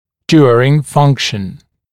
[‘djuərɪŋ ‘fʌŋkʃ(ə)n][‘дйуэрин ‘фанкш(э)н]во время функциональной нагрузки, во время функции